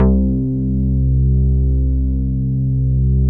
MOOG #8  C3.wav